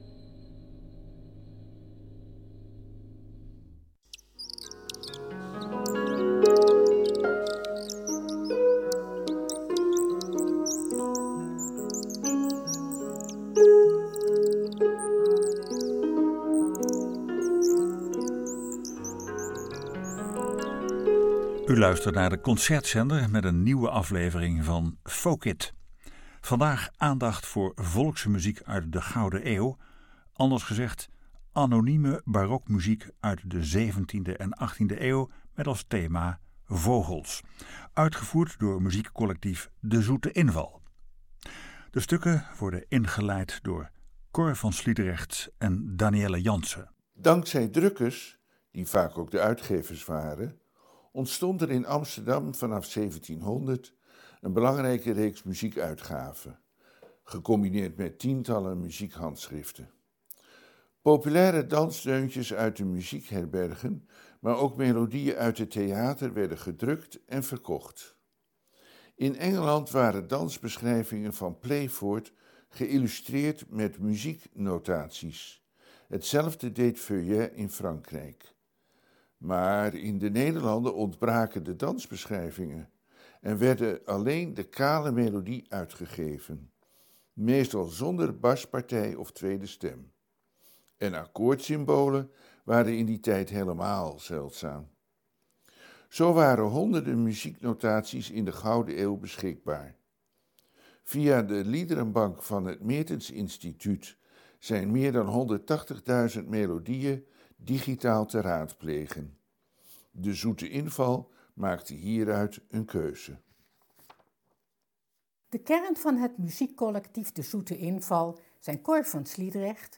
Vandaag aandacht voor volkse muziek uit de Gouden Eeuw, anders gezegd: anonieme barokmuziek uit de 17e en 18e eeuw, met als thema vogels, uitgevoerd door het muziekcollectief ‘De Zoete Inval’